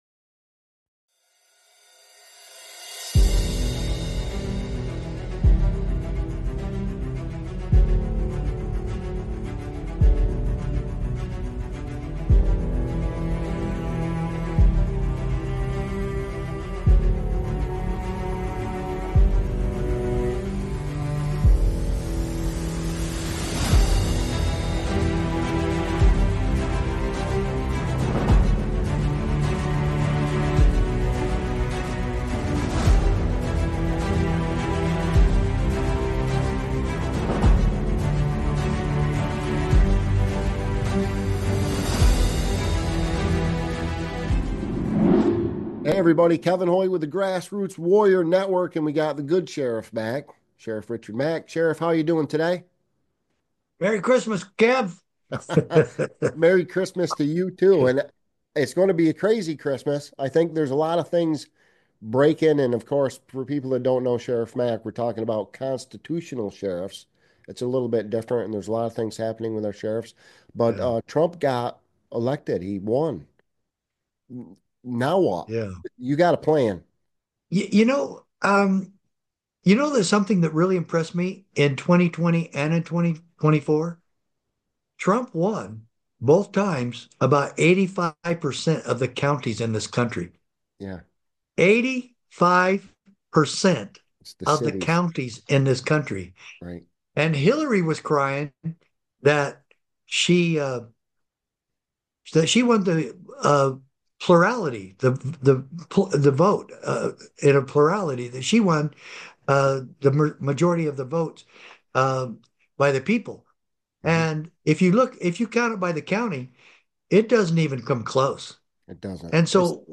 Sheriff Mack emphasized that sheriffs, being independent and only accountable to the people in their counties, play a crucial role in enforcing laws and ensuring safety. The conversation also touched on issues of corruption and the need for public pressure to ensure sheriffs uphold the constitution.